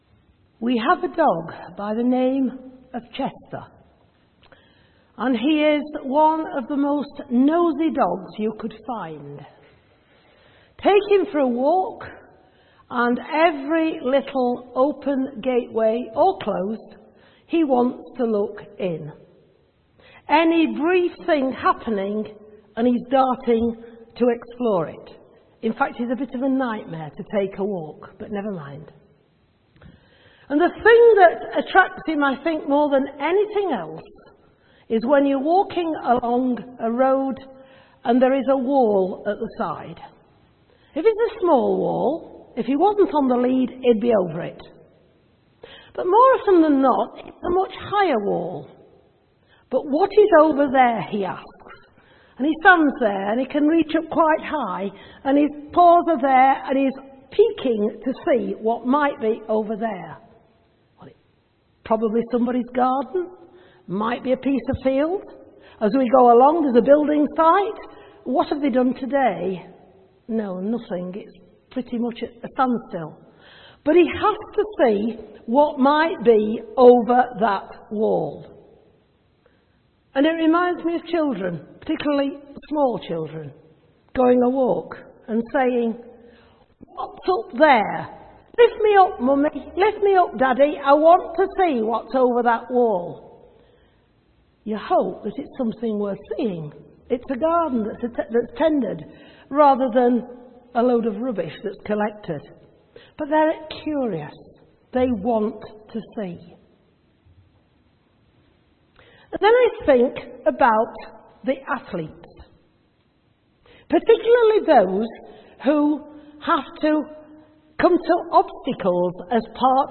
A message from the series
From Service: "6.30pm Service"